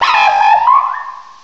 sovereignx/sound/direct_sound_samples/cries/mienfoo.aif at master